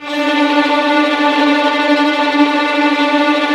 Index of /90_sSampleCDs/Roland L-CD702/VOL-1/STR_Vlns Tremelo/STR_Vls Trem wh%